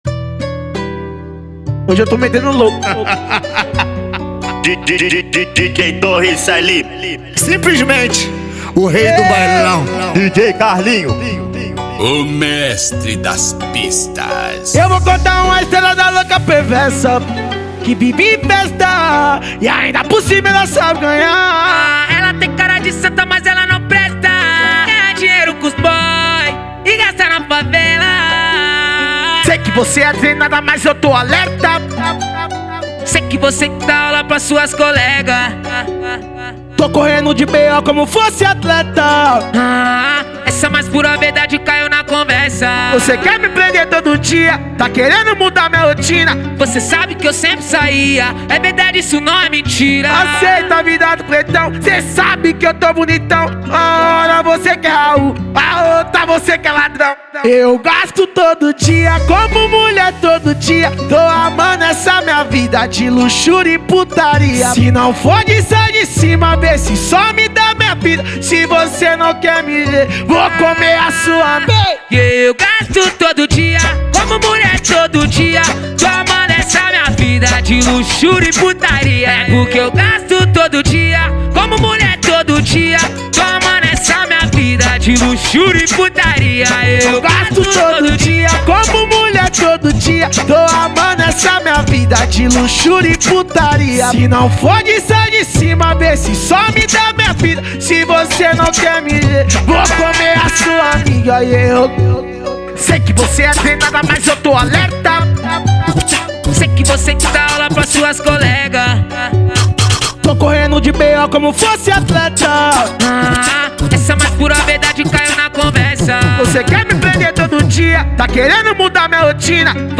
Baixar Funk